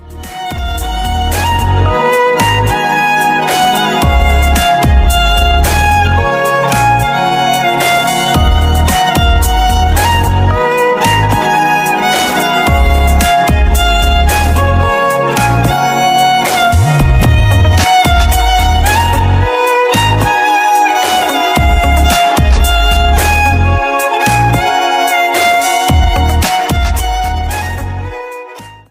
Category: Violin Ringtones